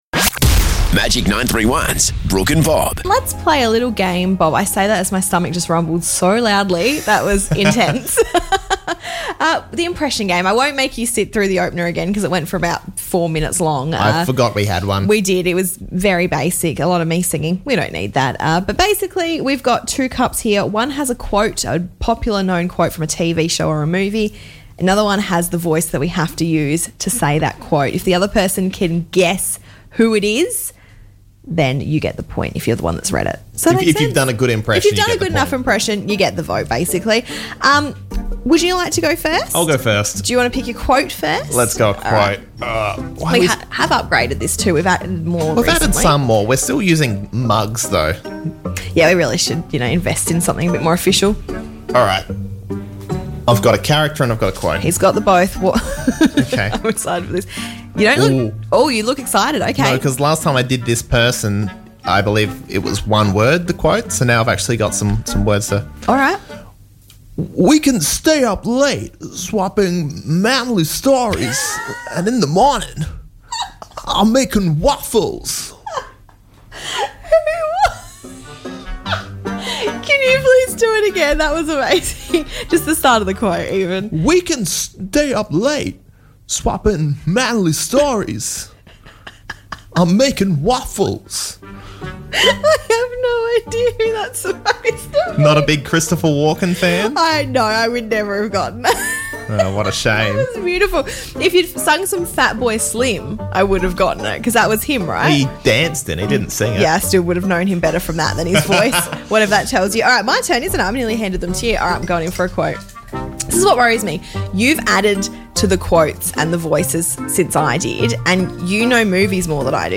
Can you guess the voice?